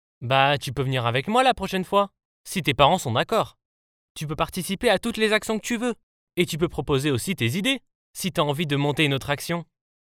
Always Voice Over Commercial Actor + Voice Over Jobs